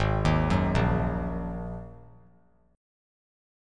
Listen to Ab7sus2 strummed